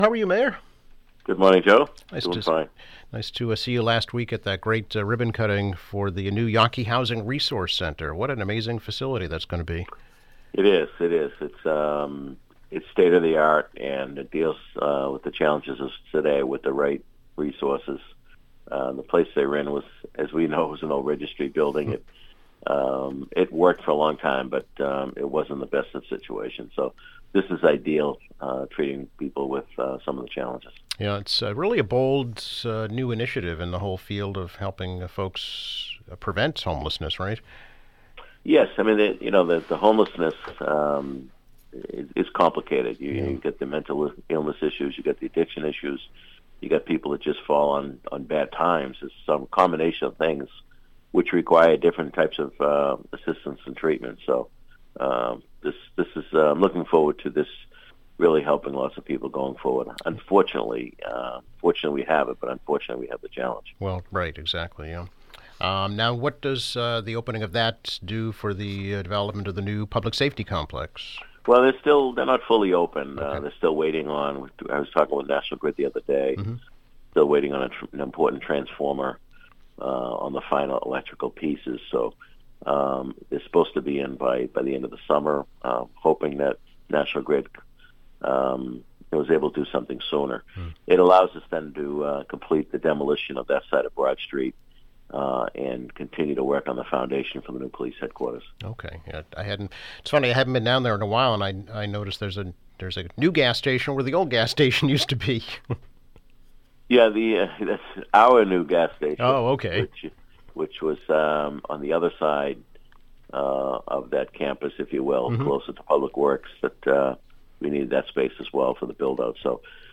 Quincy Mayor Thomas Koch comments on the new Yawkey Housing Resource Center, the new public safety complex, taking of the IHOP restaurant in Quincy Center, and plans to redevelop Wollaston Center.